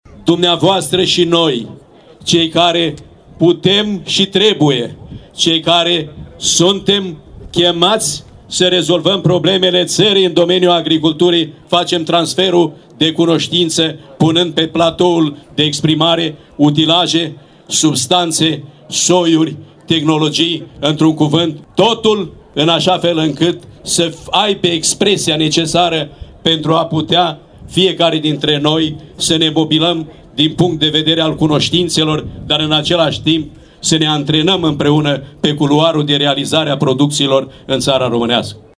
La festivitatea de deschidere a fost prezent pentru al doilea an consecutiv  și ministrul agriculturii Petre Daea, absolvent al Universității din Timișoara.